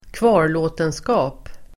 Uttal: [²kv'a:r_lå:tenska:p]